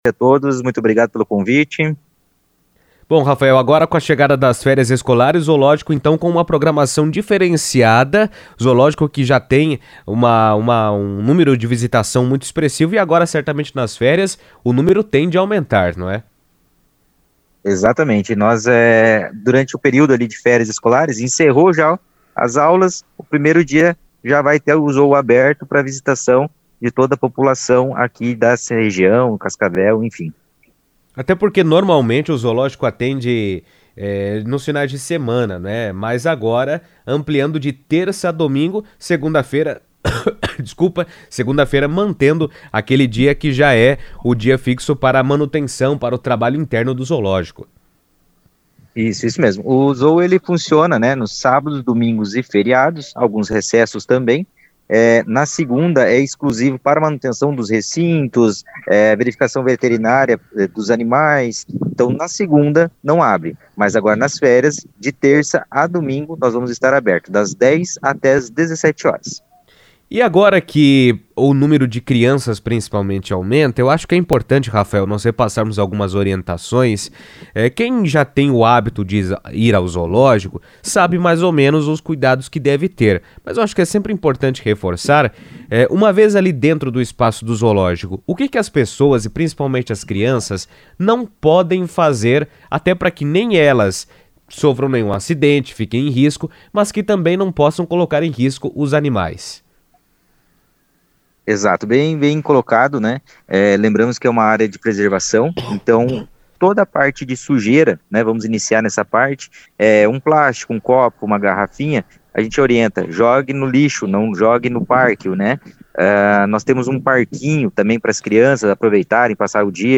falou ao vivo por telefone na CBN.